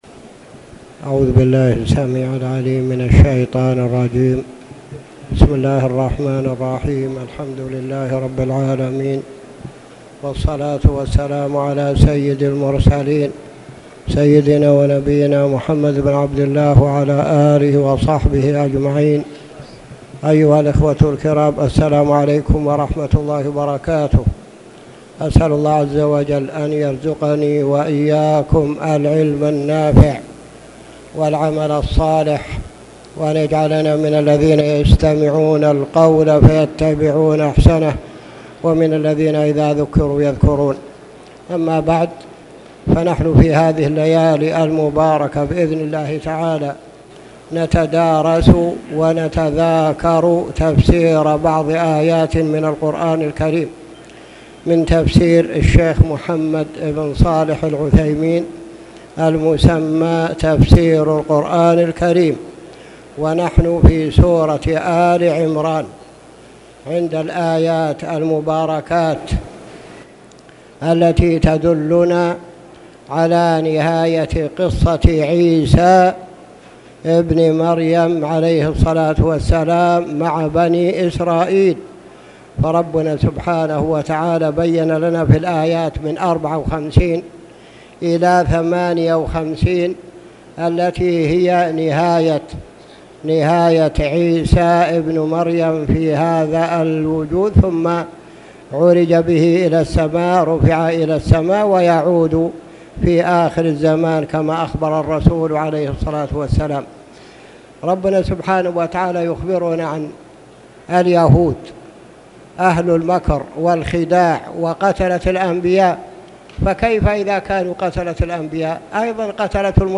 تاريخ النشر ١ جمادى الآخرة ١٤٣٨ هـ المكان: المسجد الحرام الشيخ